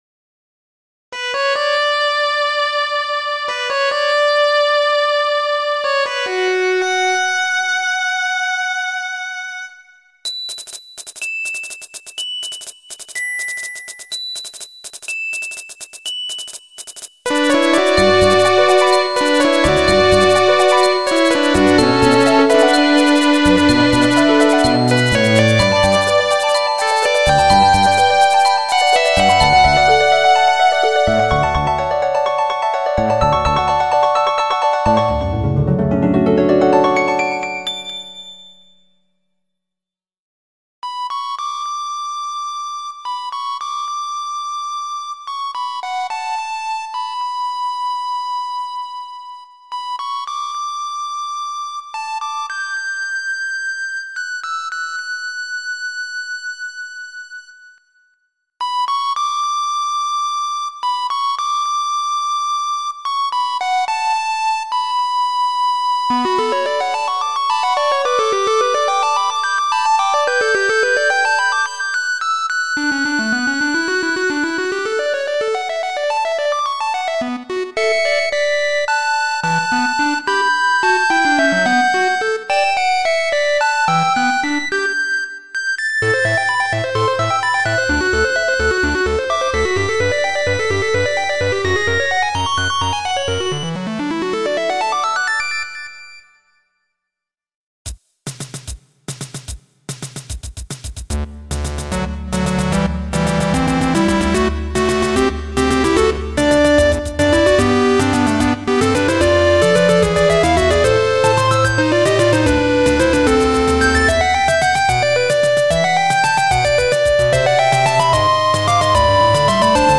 クリア後の感動と哀愁がすさまじい。
GS音源。